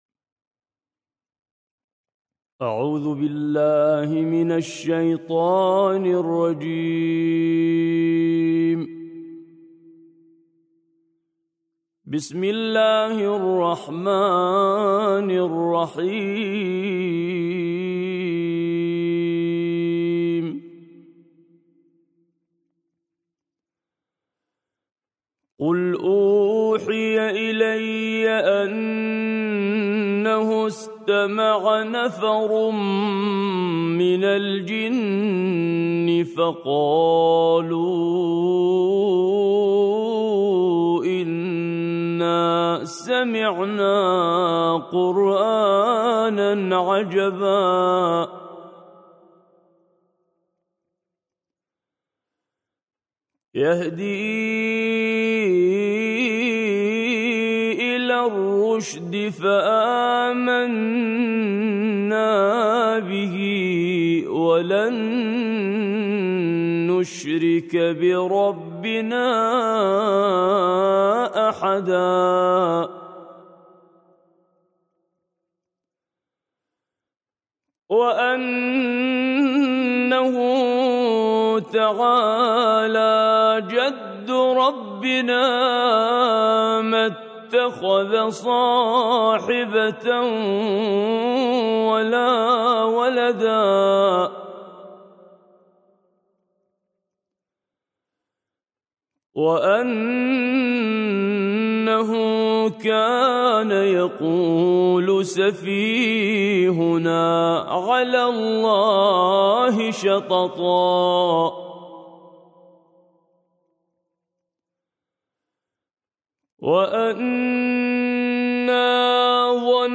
سورة الجن - الطور العراقي - لحفظ الملف في مجلد خاص اضغط بالزر الأيمن هنا ثم اختر (حفظ الهدف باسم - Save Target As) واختر المكان المناسب